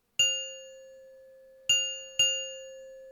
Guide des carillons
Diving_hummingbird.ogg